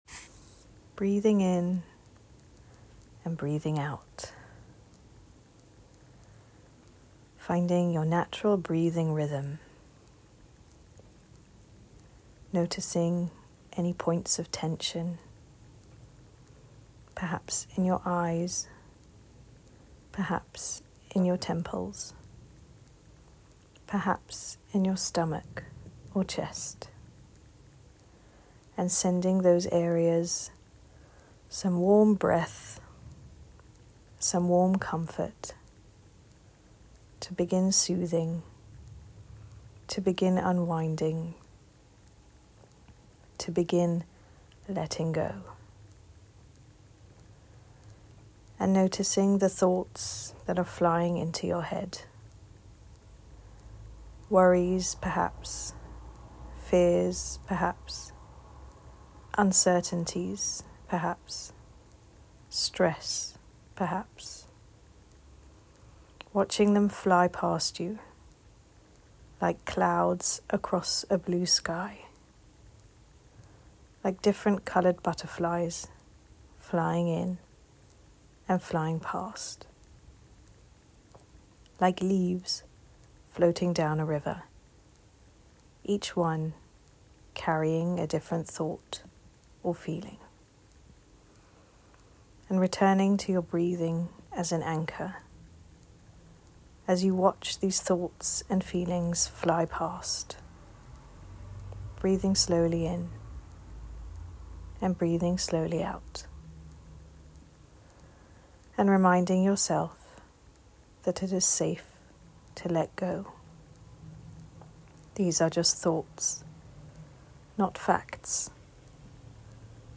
Enjoy and feel free to share TorahPsych’s collection of breathing and visualisation exercises to help you calmly tolerate feelings.